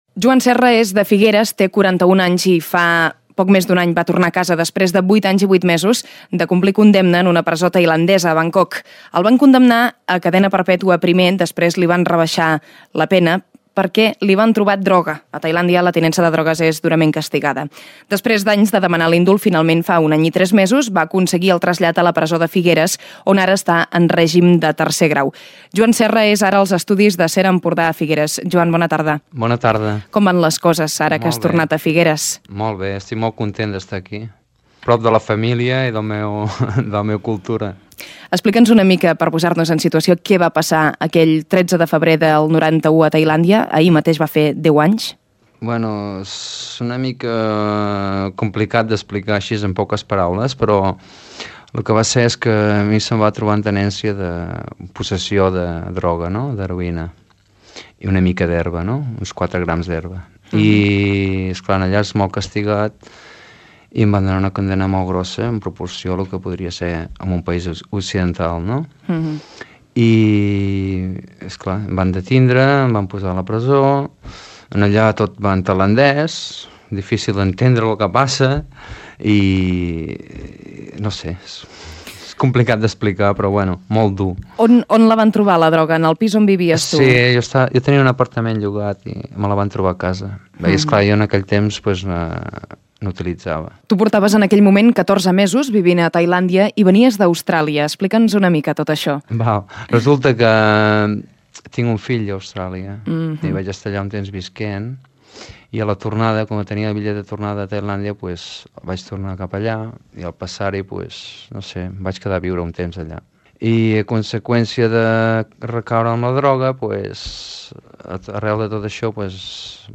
El Balcó: entrevista